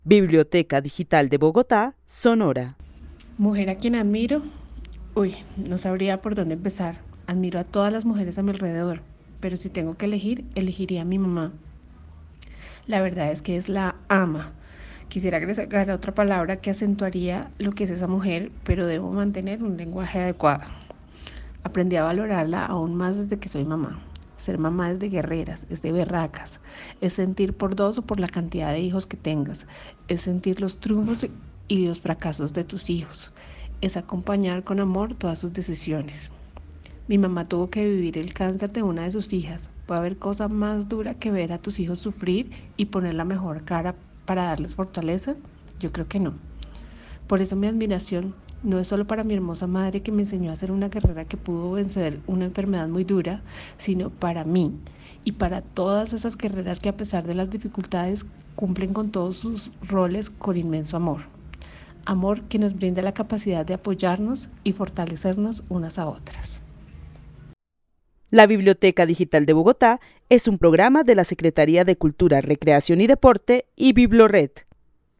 Narración oral de una mujer que vive en la ciudad de Bogotá y que admira a todas las mujeres de su alrededor, especialmente a su mamá a quien empezó a valorar desde que ella es mamá porque es un rol es de guerreras y berracas, ya que es sentir por dos o por la cantidad de hijos que se tengan y acompañar con amor. Destaca un proceso personal en el que considera que su mamá fue muy fuerte.
El testimonio fue recolectado en el marco del laboratorio de co-creación "Postales sonoras: mujeres escuchando mujeres" de la línea Cultura Digital e Innovación de la Red Distrital de Bibliotecas Públicas de Bogotá - BibloRed.